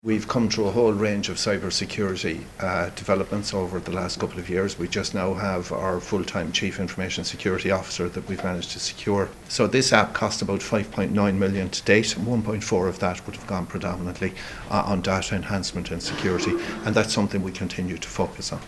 The HSE’s chief executive, Bernard Gloster, says significant work has gone into ensuring medical data will be protected: